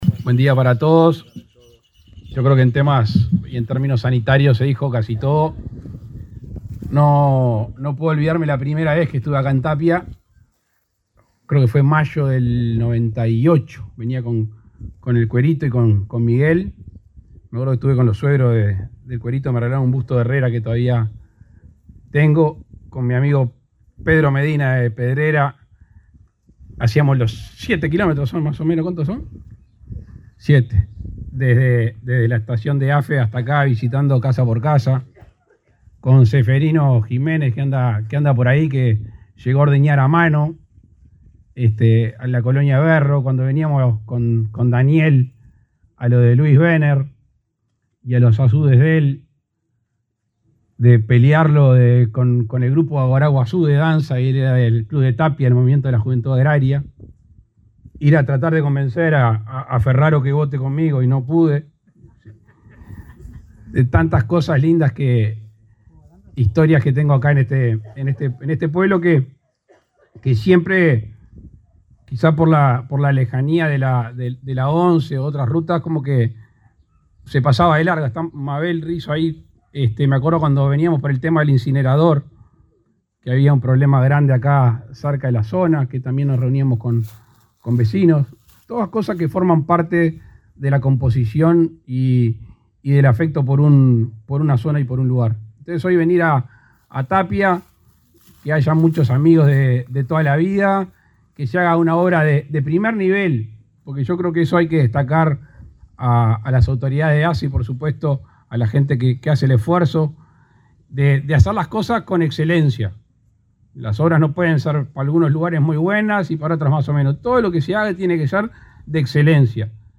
Palabras del presidente Luis Lacalle Pou
Este jueves 26, el presidente Luis Lacalle Pou participó en la inauguración de las obras de remodelación de la policlínica de la localidad de Tapia,